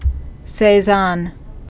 (sā-zŏn, -zôn, sĕ-zôɴ)